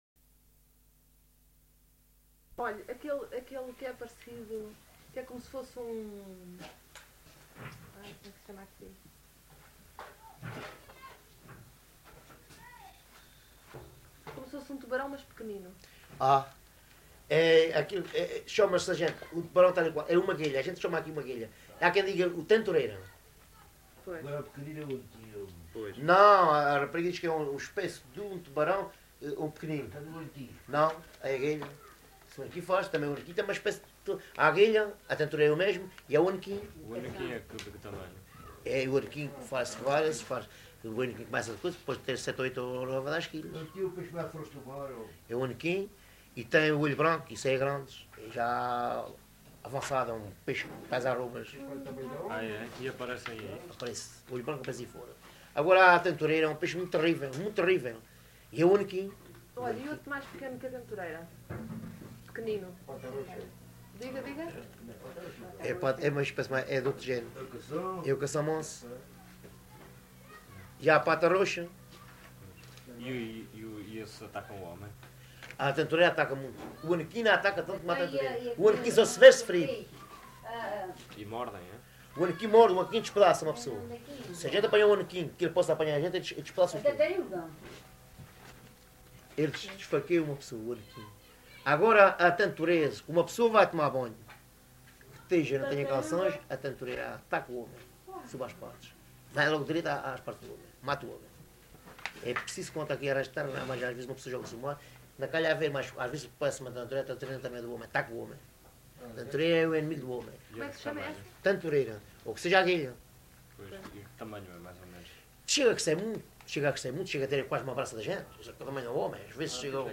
LocalidadeAlvor (Portimão, Faro)